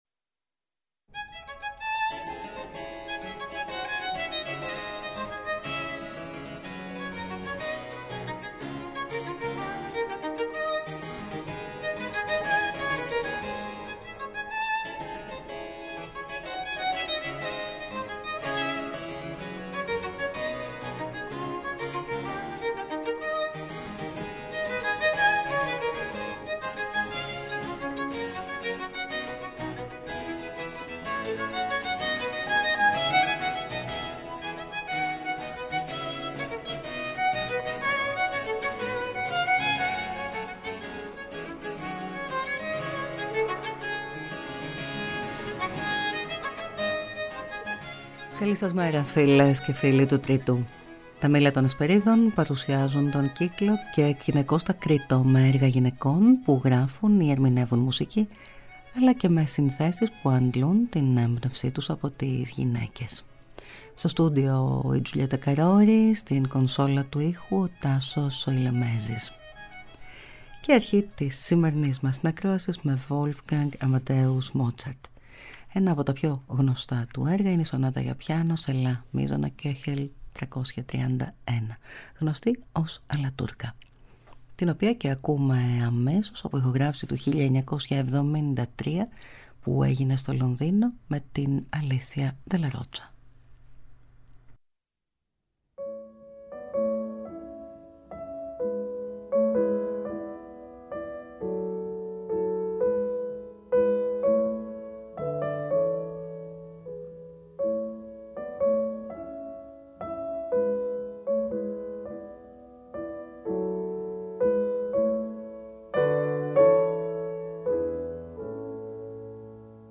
Σονάτα για πιάνο σε Λα Μείζονα